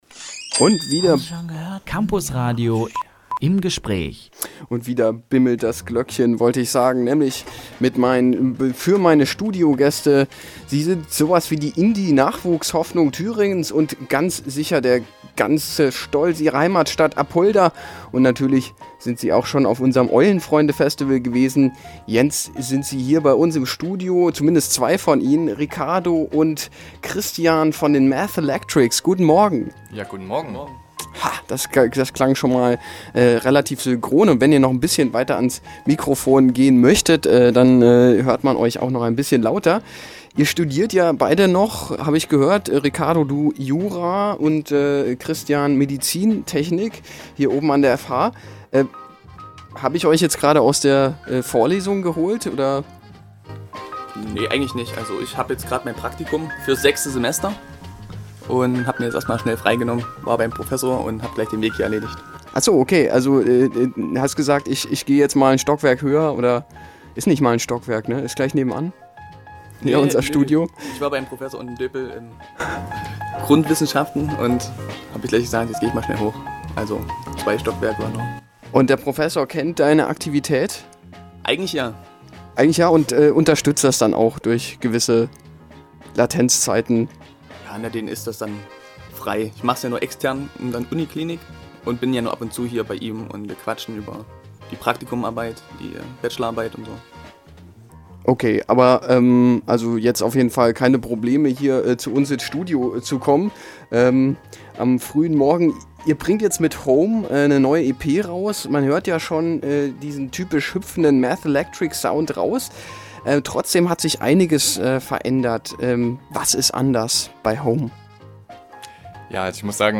Mathelectrics mit neuer EP – im Studiointerview – Campusradio Jena